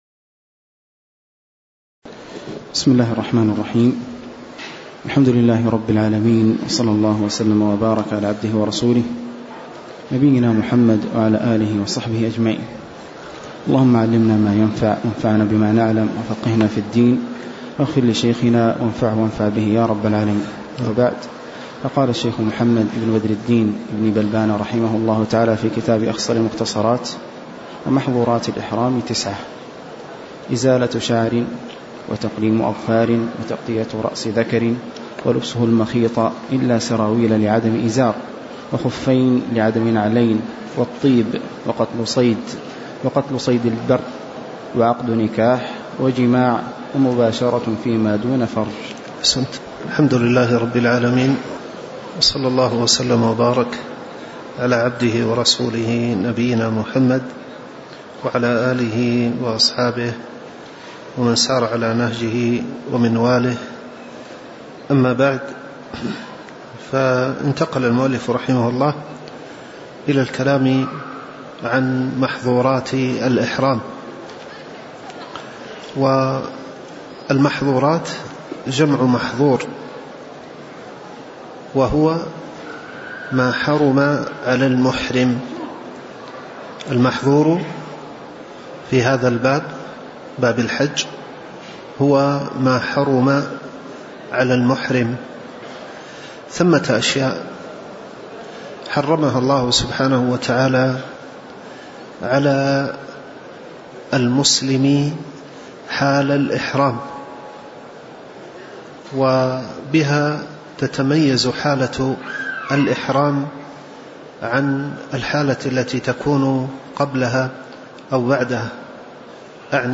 تاريخ النشر ١٥ صفر ١٤٤٠ هـ المكان: المسجد النبوي الشيخ